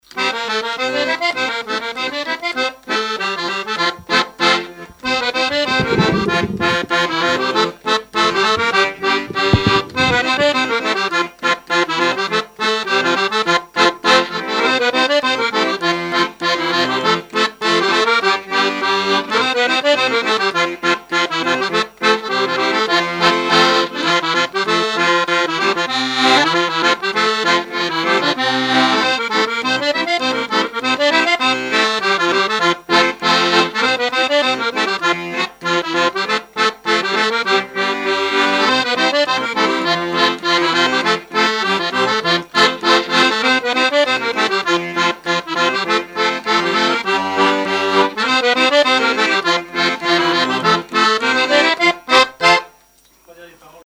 Saint-Christophe-du-Ligneron
danse : scottich trois pas
Genre brève
Pièce musicale inédite